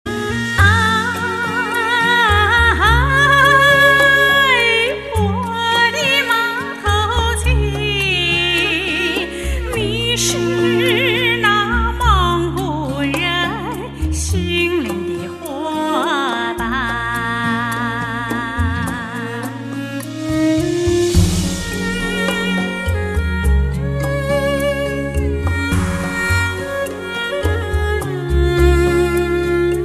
M4R铃声, MP3铃声, 华语歌曲 54 首发日期：2018-05-15 15:14 星期二